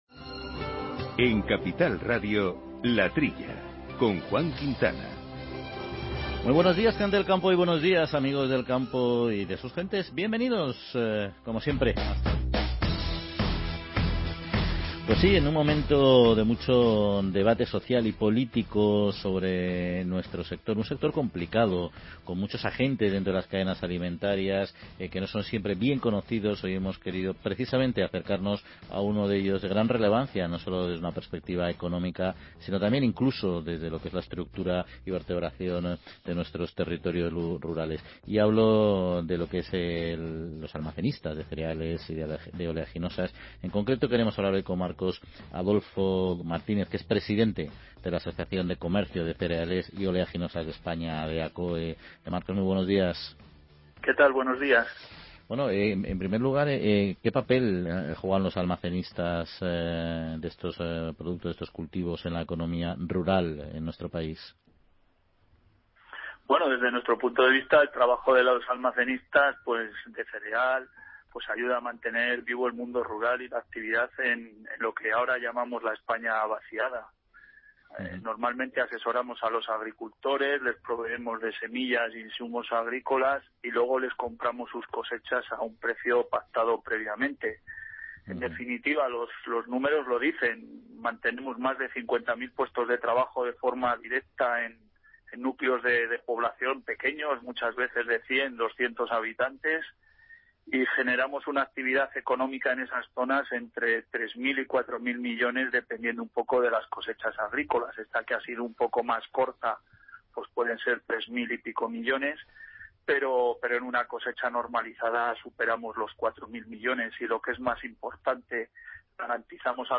En dicha entrevista se han realizado preguntas tan relevantes como: